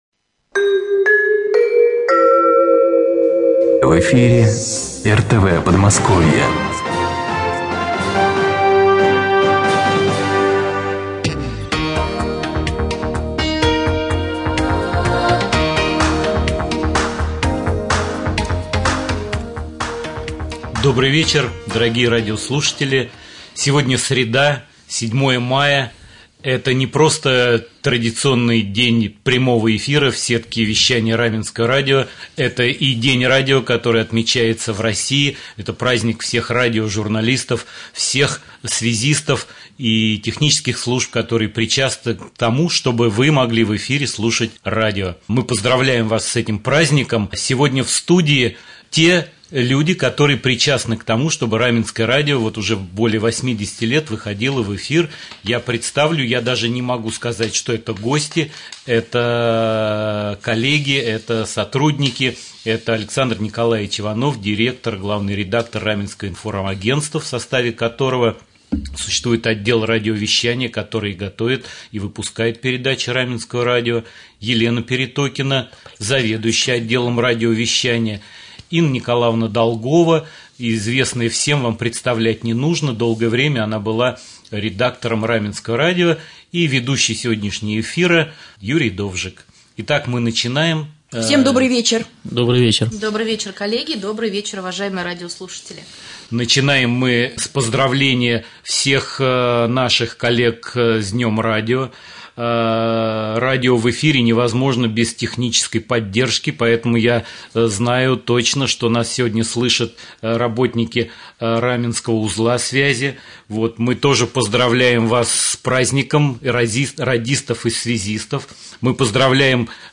1.Прямой эфир, посвященный Дню радио.
1.Прямой-эфир.mp3